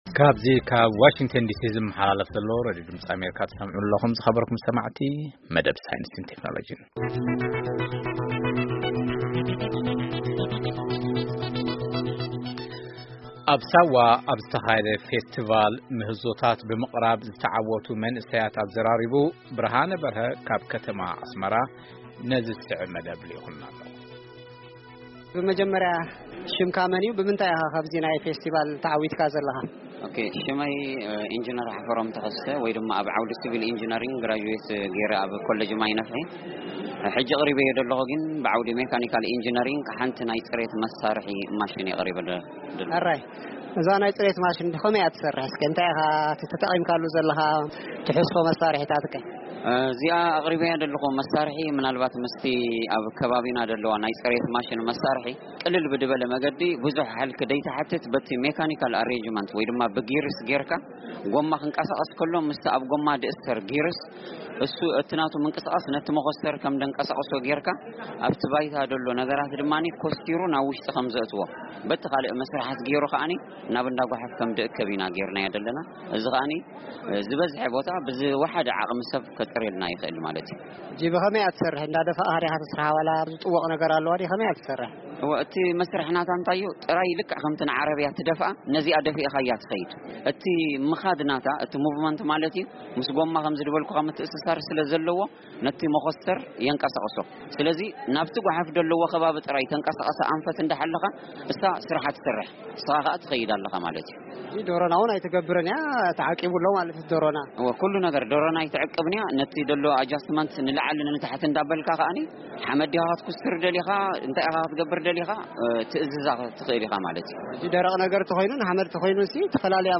ቃለ-መጠይቕ ተዓወትቲ